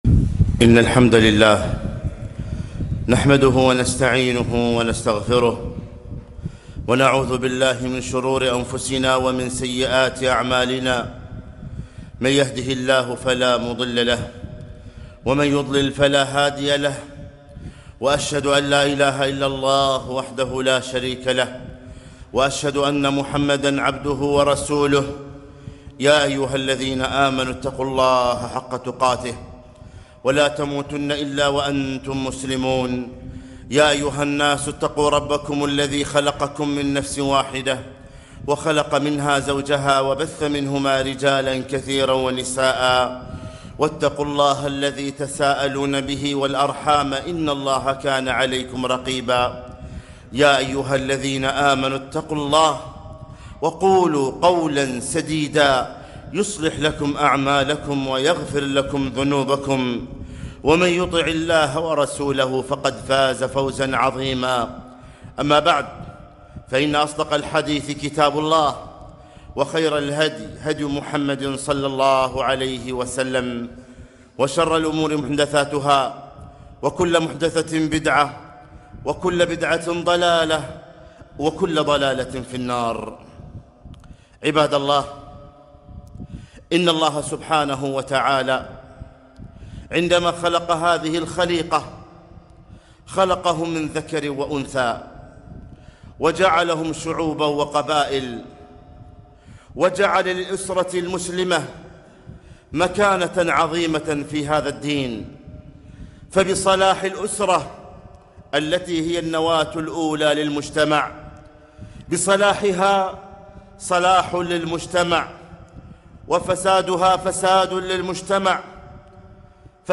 خطبة - بناء البيت المسلم